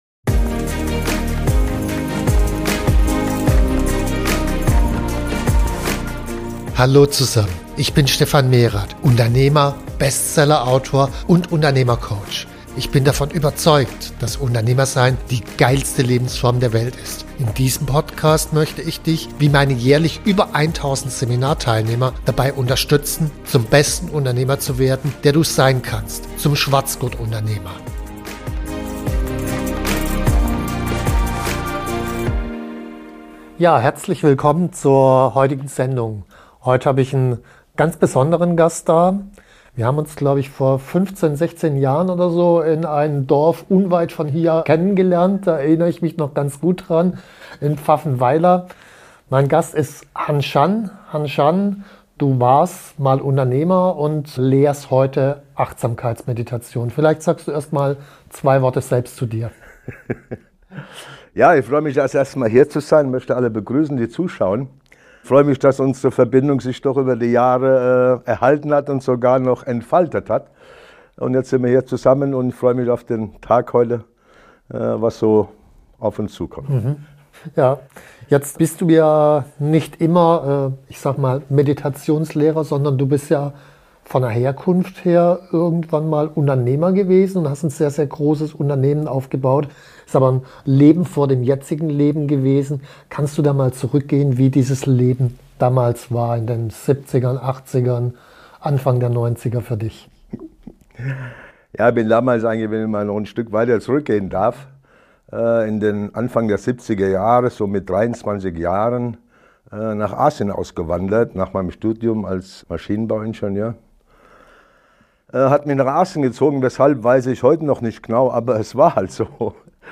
In diesem Gespräch geht es um: • warum ein Unternehmer oft der energetische Ausgangspunkt seines Unternehmens ist